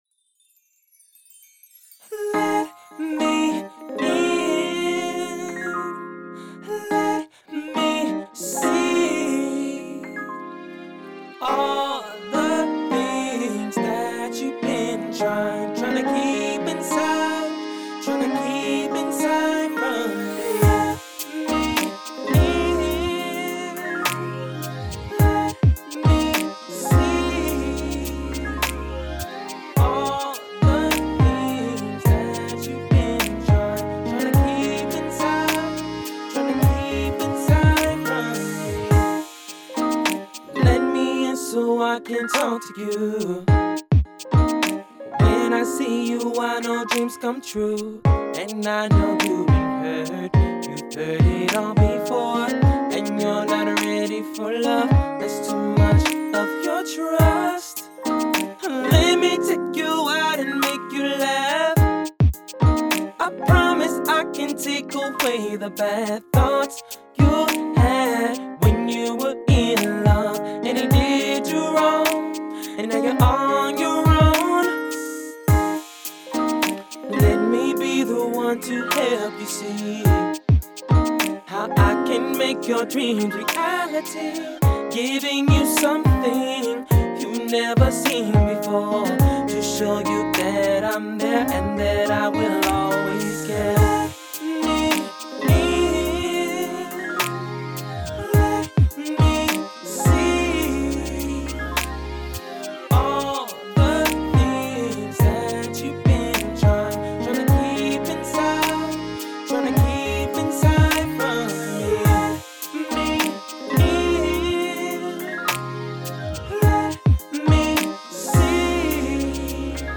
Love song that's for the lady's